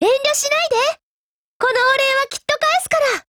贡献 ） 协议：Copyright，其他分类： 分类:少女前线:UMP9 、 分类:语音 您不可以覆盖此文件。